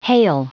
Prononciation du mot hail en anglais (fichier audio)
Prononciation du mot : hail